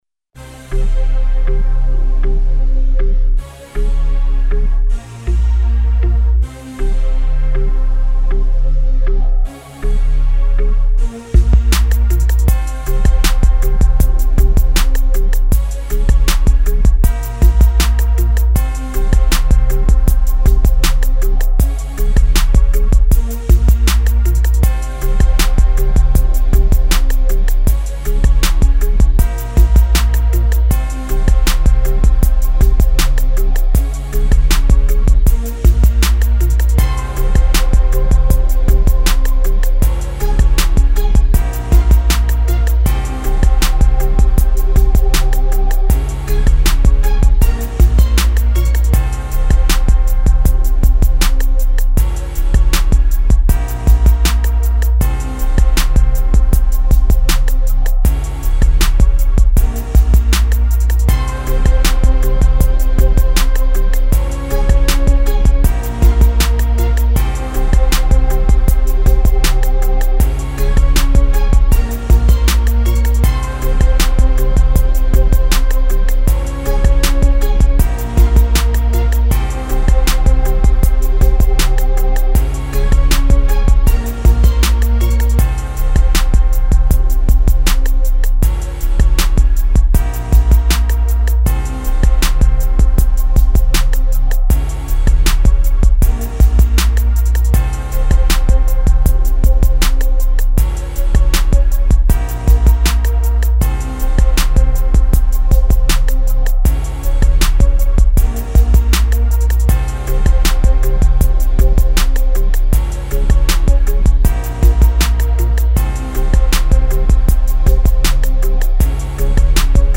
160 BPM.